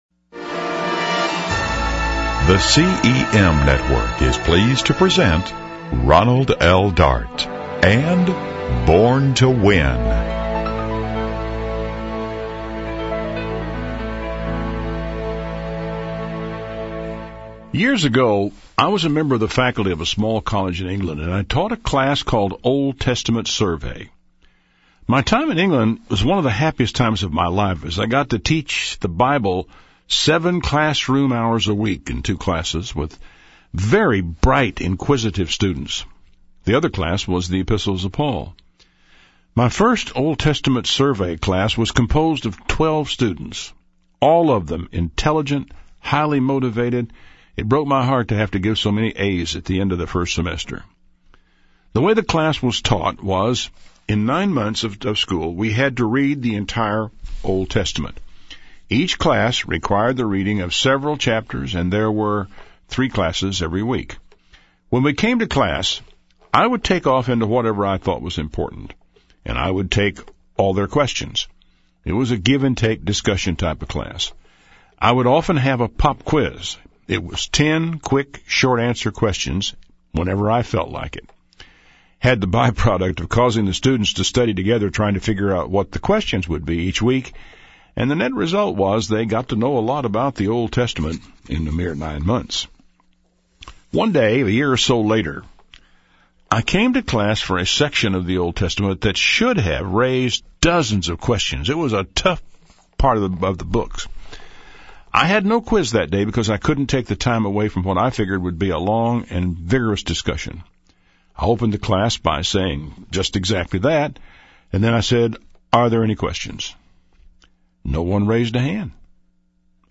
His simple style, insights and unique gift for clarity hold an audience and cause people to return program after program.